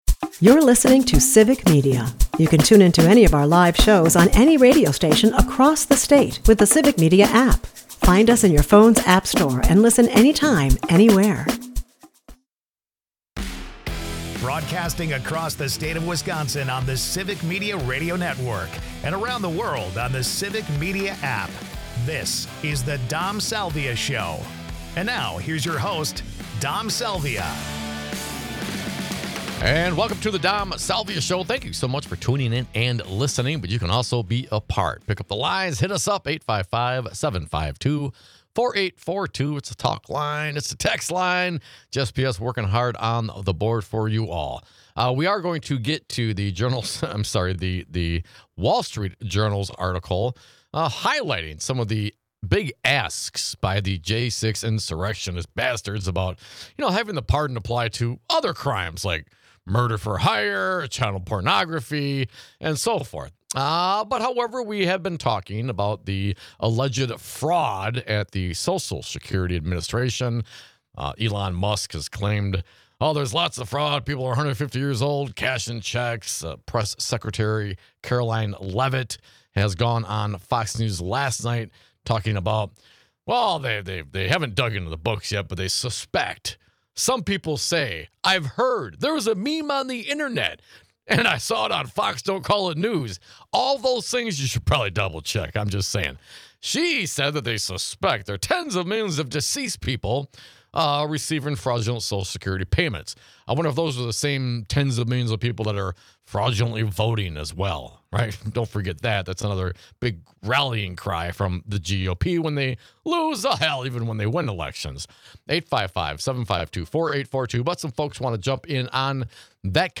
Well, we hear personal testimony and attestations to the contrary, over and over again, from our audience. They share stories with us of their experiences with the immediate revocation and recuperation of Social Security funds following loved ones' deaths.